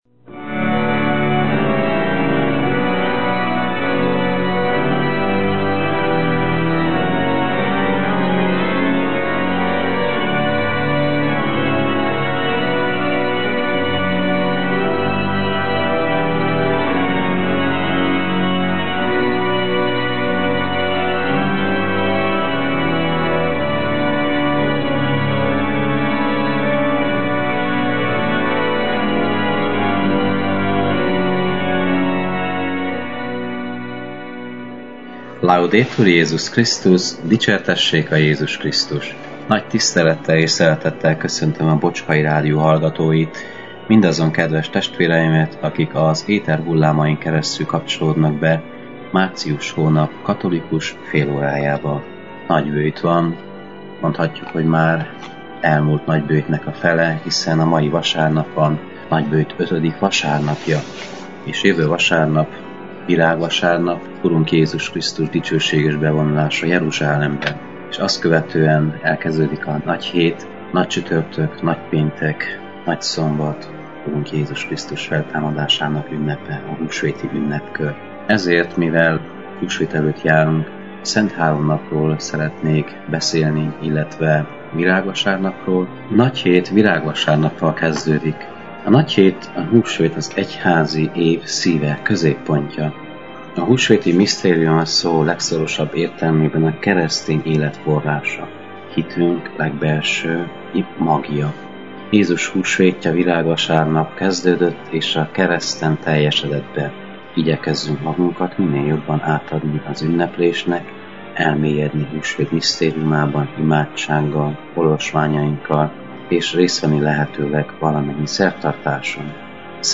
a clevelandi Szent Imre Katolikus Templomból.